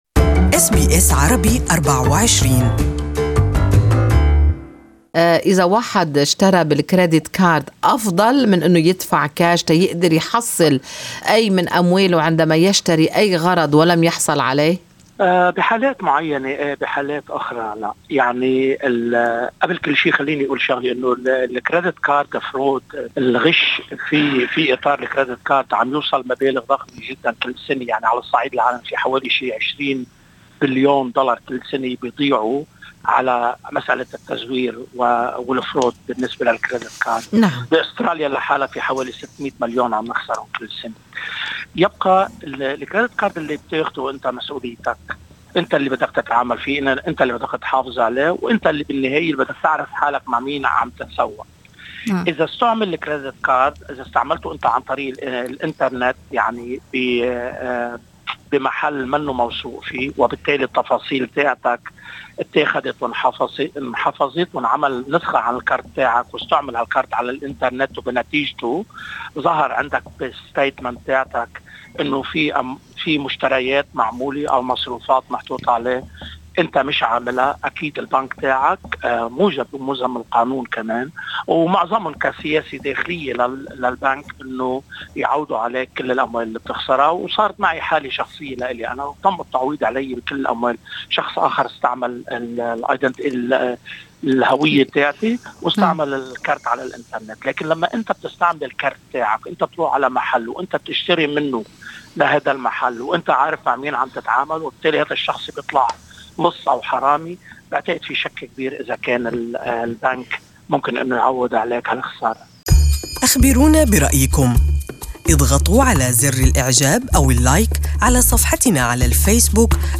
Good Morning Australia interviewed